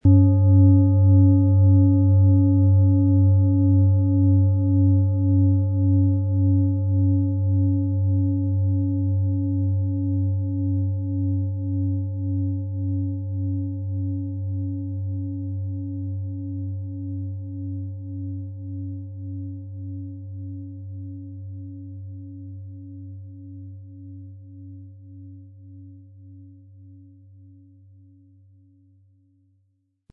Diese von Hand getriebene Planetenschale "Biorhythmus Körper" wurde in einer kleinen Manufaktur gefertigt.
PlanetentonBiorythmus Körper & Sonne (Höchster Ton)
MaterialBronze